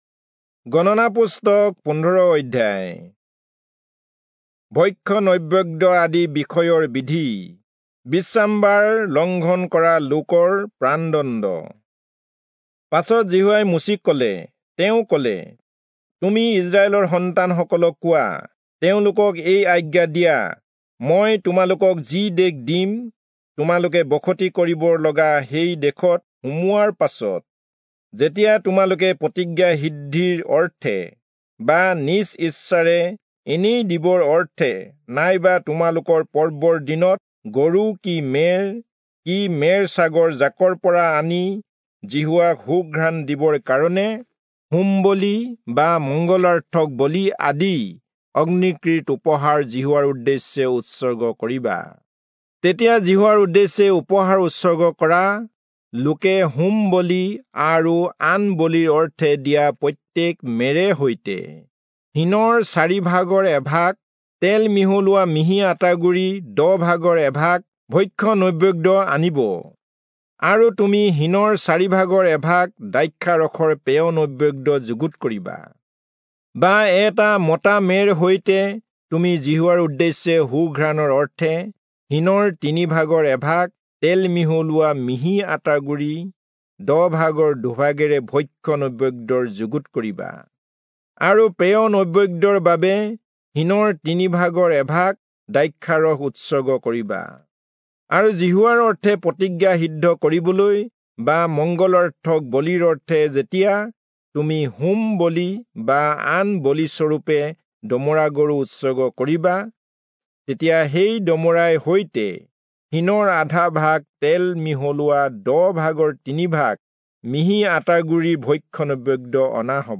Assamese Audio Bible - Numbers 8 in Mhb bible version